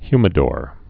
(hymĭ-dôr)